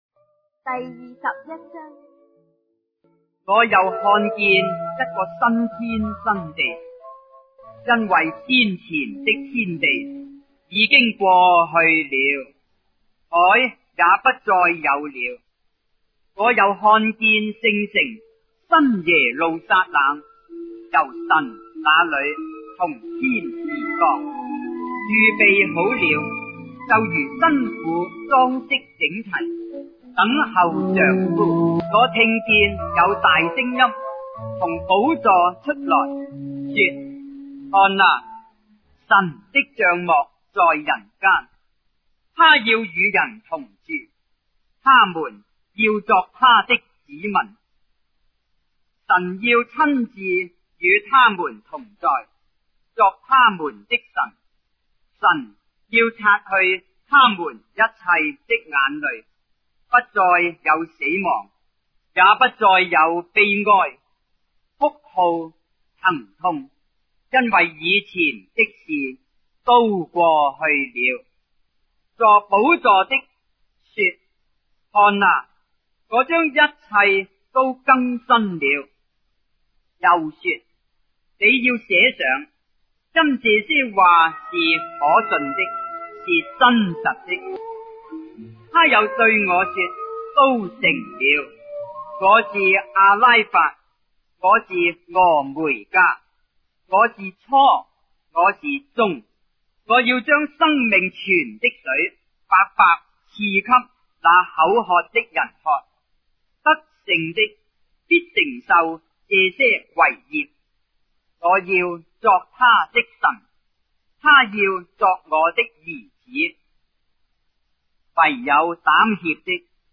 章的聖經在中國的語言，音頻旁白- Revelation, chapter 21 of the Holy Bible in Traditional Chinese